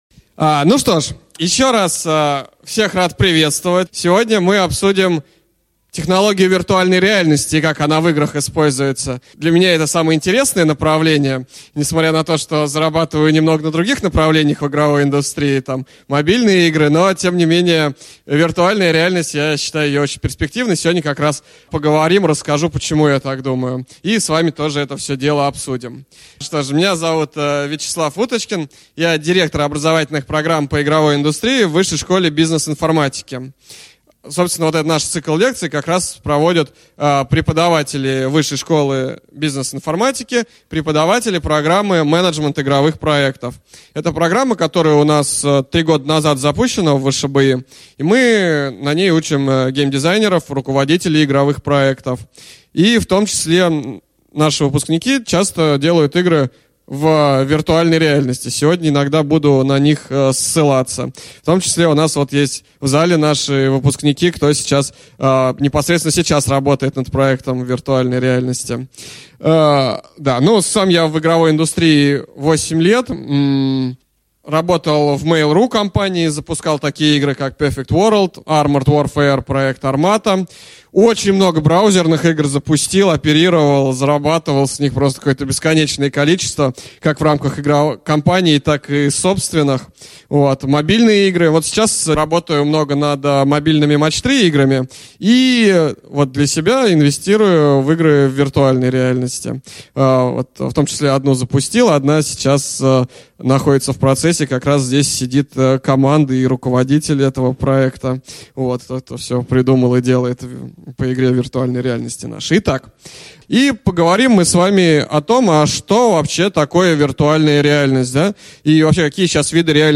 Аудиокнига Виртуальная реальность в игровой индустрии | Библиотека аудиокниг